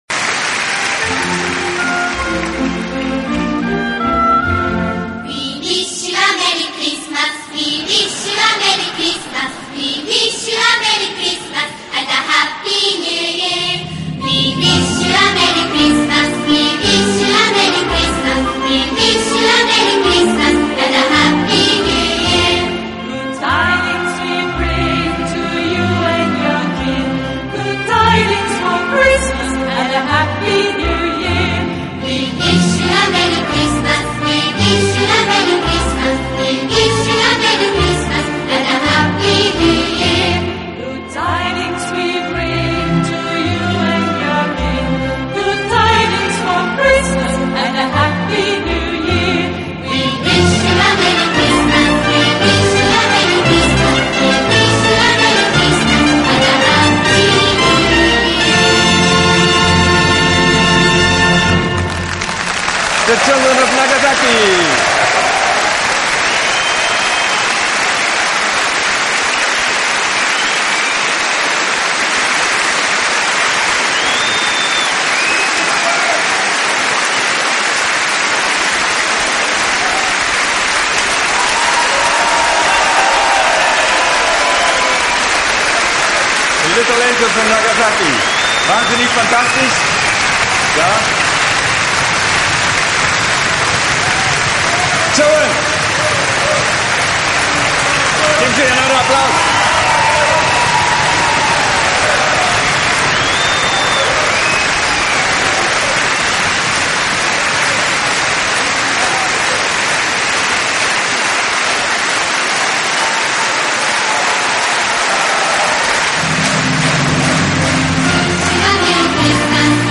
的演出方式，自己边拉小提琴边指挥乐队。